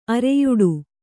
♪ areyuḍu